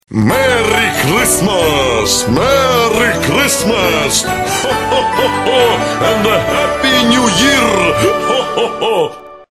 Прикольные рингтоны